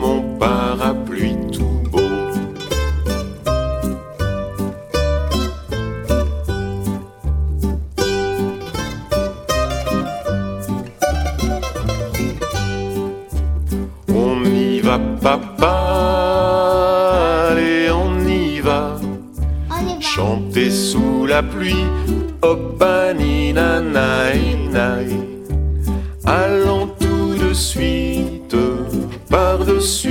0 => "Rondes et comptines"